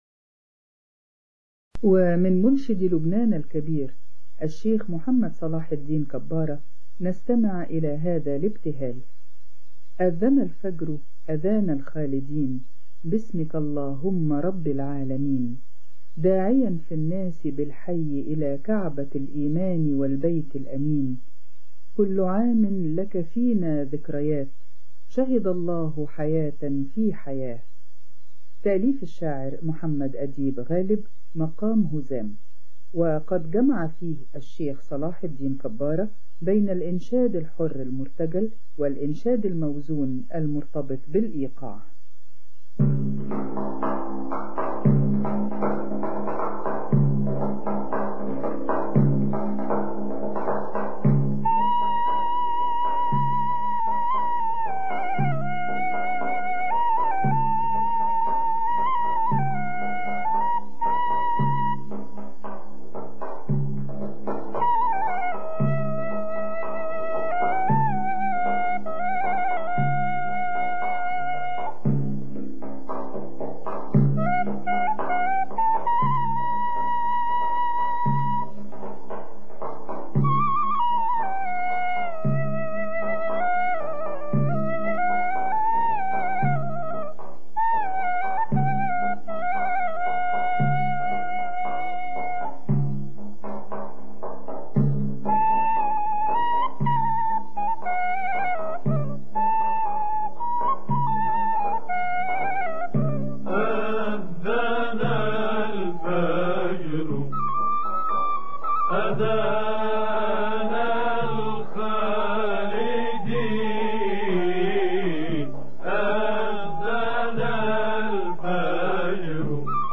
أناشيد إسلامية
مقام هزام